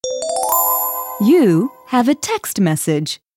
SMS Tone